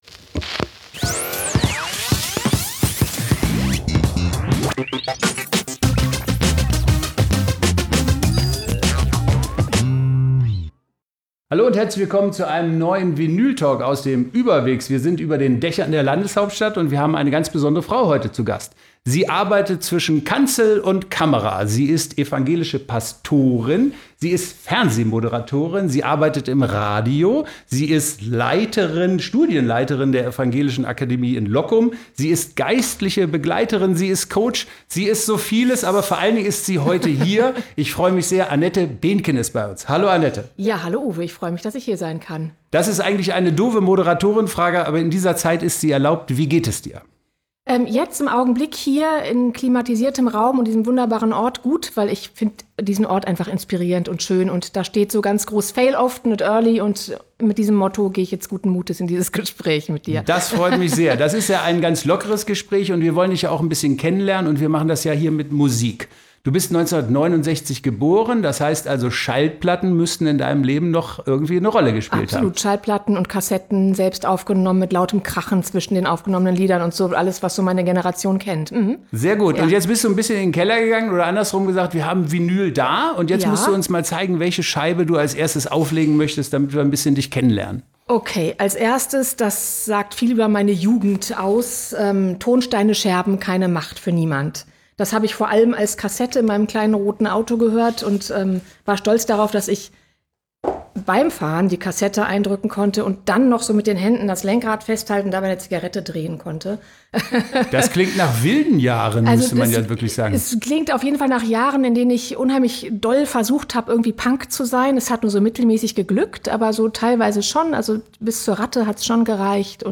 Im vinyltalk verrät das engagierte Multitalent, wie sie auch mal einen “Shitstorm” erlebt, weshalb Carlos Santana ihre Kreativität entfachte und welchen USP die Kirche zukünftig verfolgen sollte. Ein sympathisches Gespräch zwischen Kanzel und Kamera.